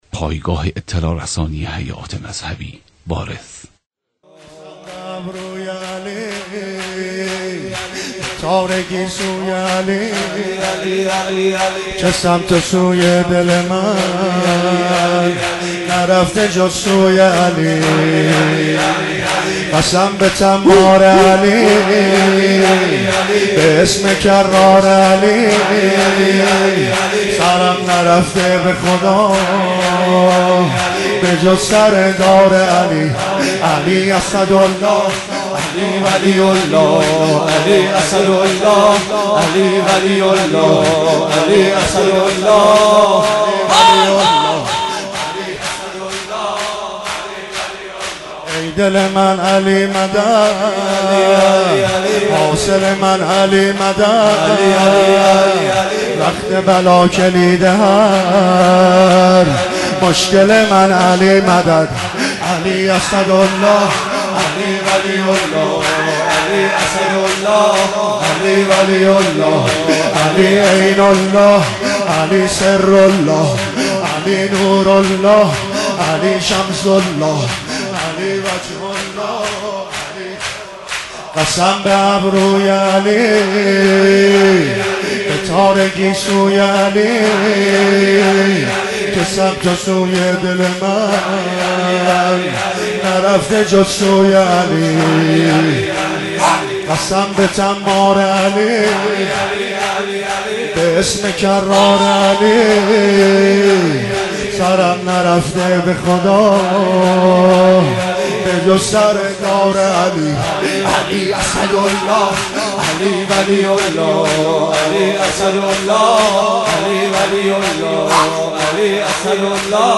مداحی حاج عبدالرضا هلالی به مناسبت شهادت امیرالمومنین (ع)